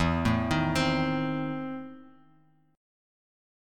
EM7sus4#5 chord